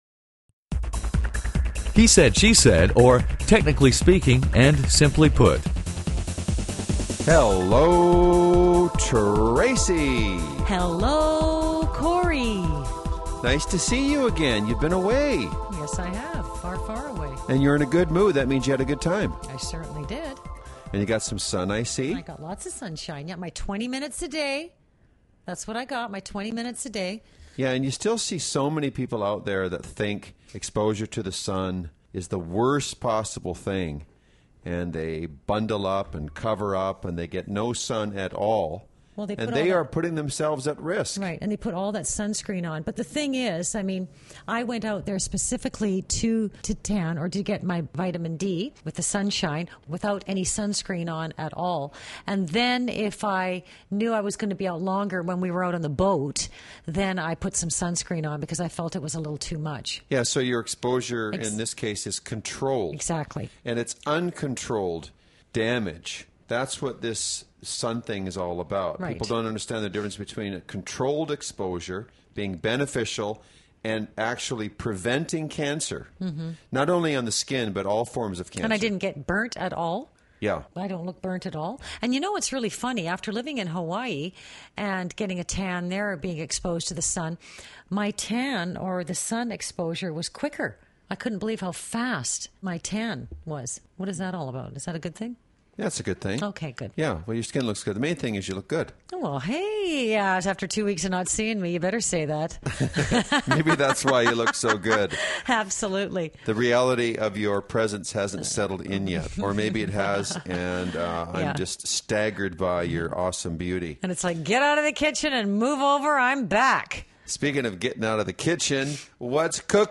spontaneous and humorous dialog